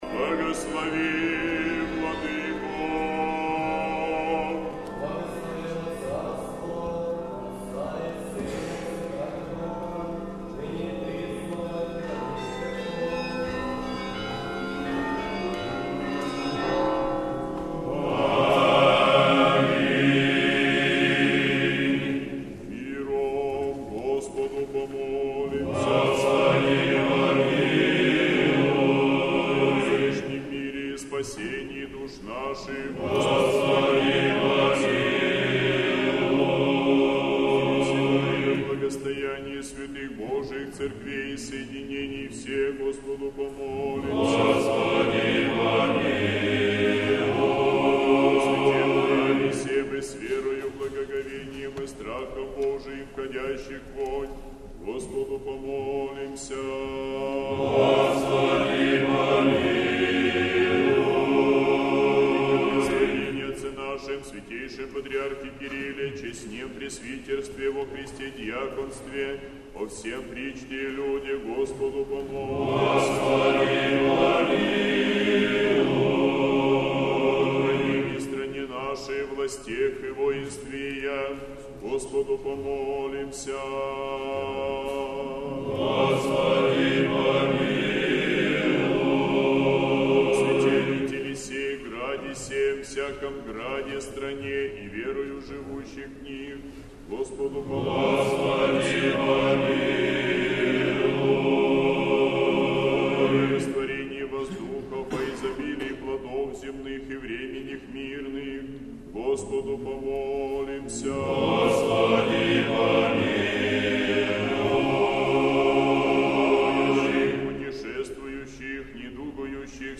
Божественная литургия. Хор Сретенского монастыря.
Божественная литургия в Сретенском монастыре в Неделю 1-ю по Пятидесятнице, Всех святых